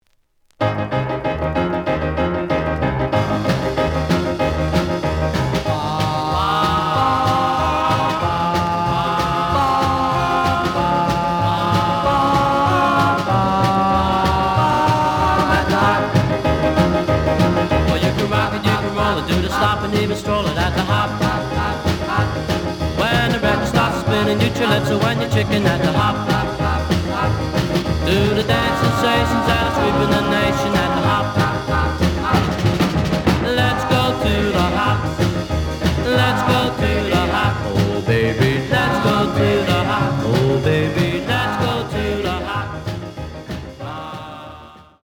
試聴は実際のレコードから録音しています。
The audio sample is recorded from the actual item.
●Genre: Rhythm And Blues / Rock 'n' Roll